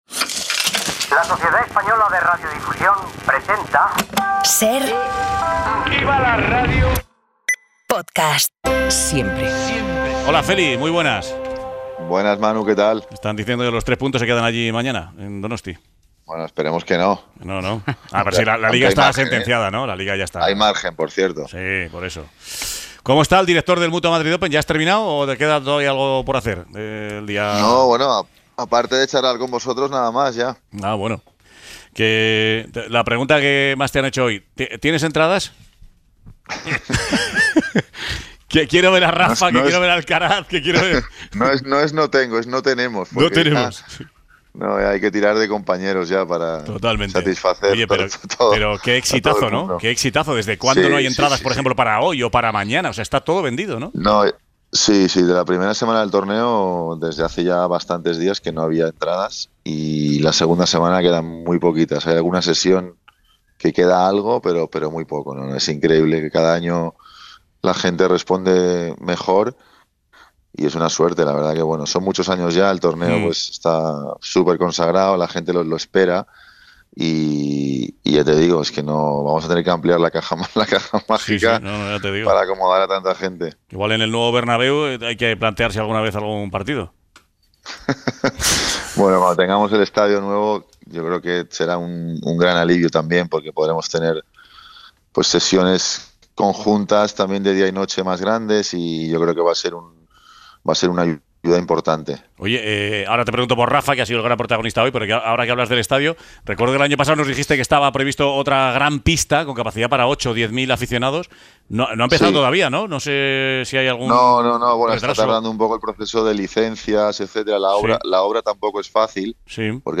Charlamos con Feliciano López, extenista y director del Mutua Madrid Open sobre toda la actualidad del torneo en la capital, la vuelta y posible despedida de Nadal el próximo sábado o las críticas de Roberto Bautista sobre las invitaciones al torneo.